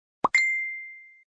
MessengerSound.mp3